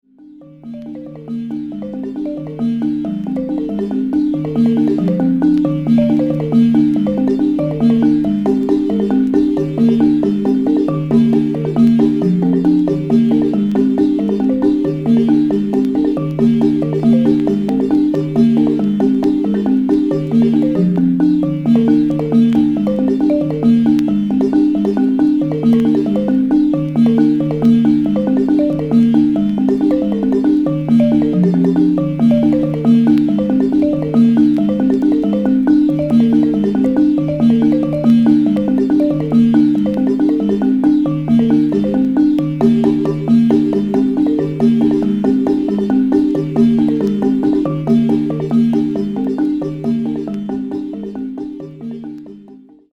西アフリカ諸国に太古から伝わるひょうたん木琴コギリ。
ひょうたんリゾネーターの丸みのある余韻と悠久のリズムが生み出すたゆたうような陶酔感に思わずうっとりしちゃう大名盤。
キーワード：アフリカ　民族音楽　創作楽器